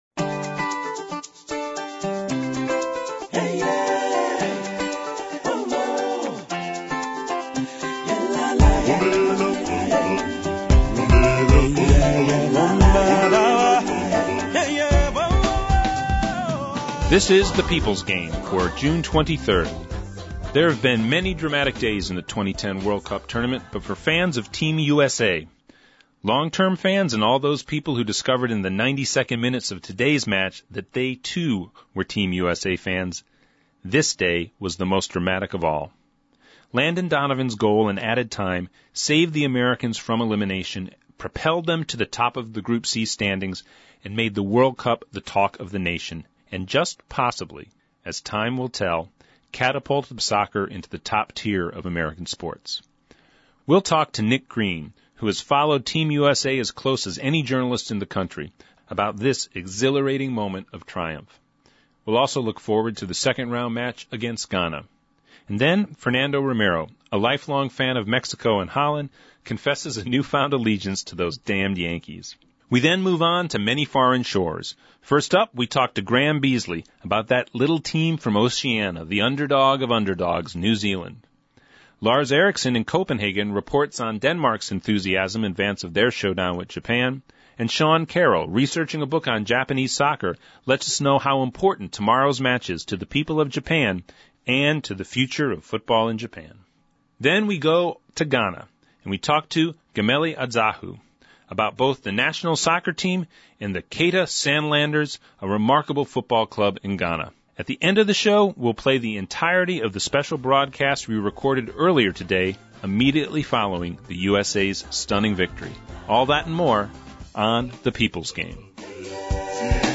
The People’s Game – Radio Show for June 23, 2010
To the end the show, we play the entirety of the special broadcast we recorded earlier today immediately following the USA’s stunning victory.